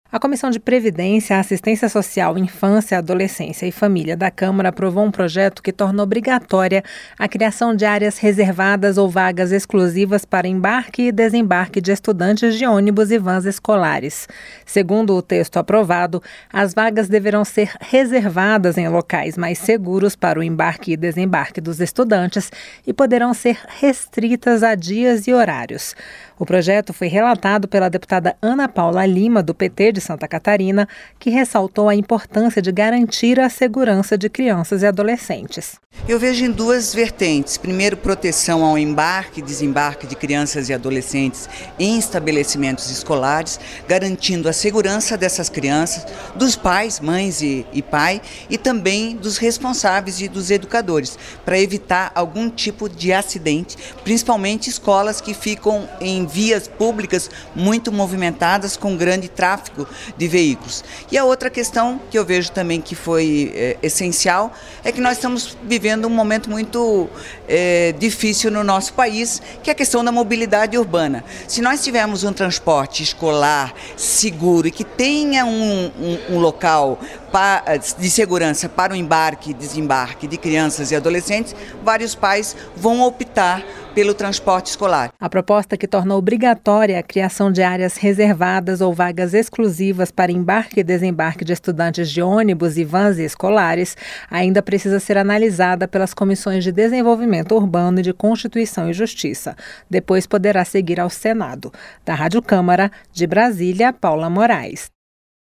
AVANÇA NA CÂMARA UMA PROPOSTA QUE RESERVA VAGAS PARA ÔNIBUS E VANS ESCOLARES. A REPORTAGEM